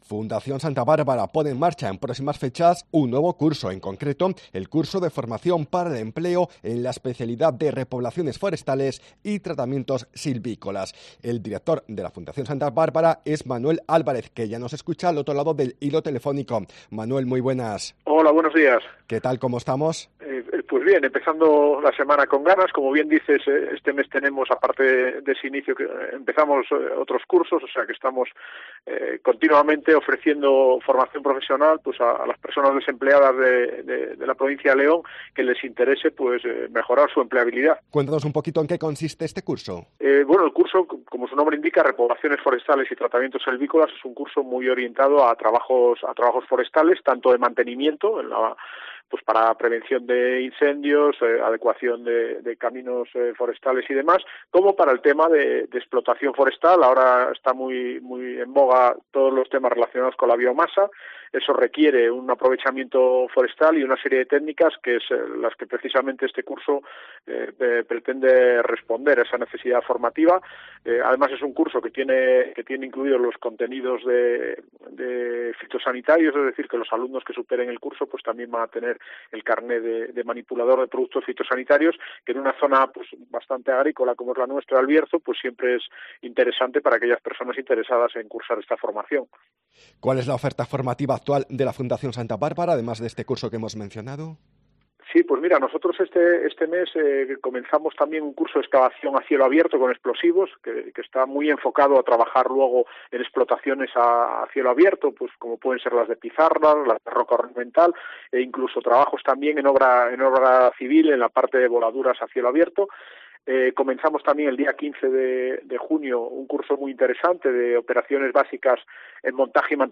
SOCIEDAD-MEDIODÍA COPE